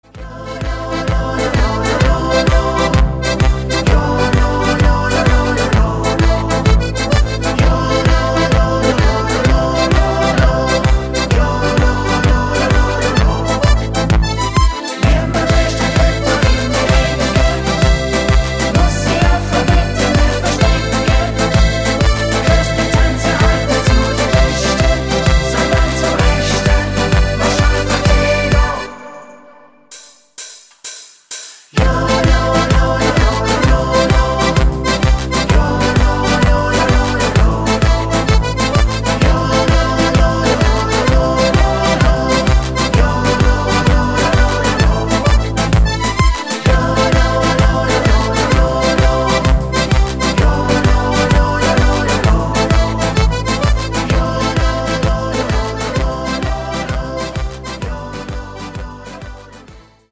Super Stimmungshit